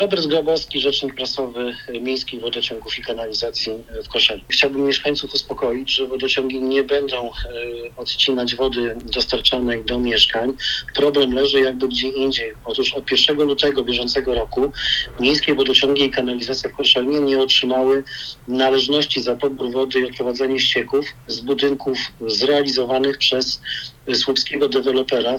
wodociagi-stanowisko.mp3